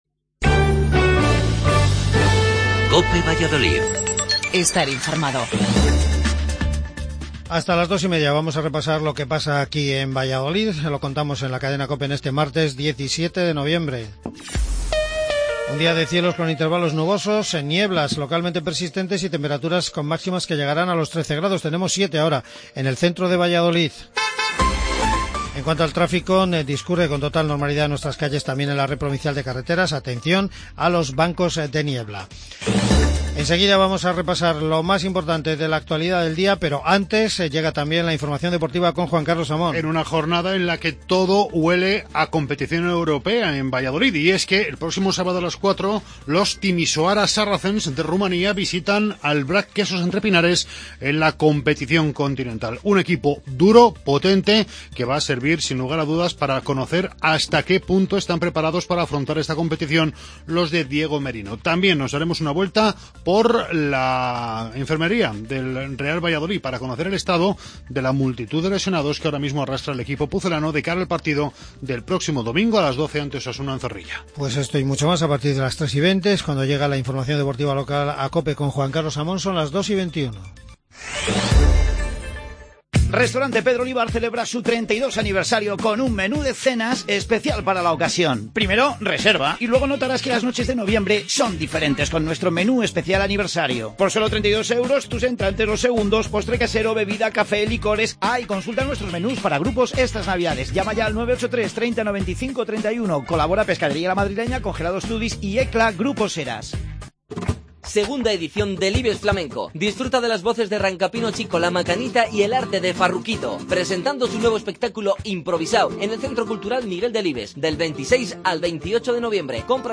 AUDIO: Informativo local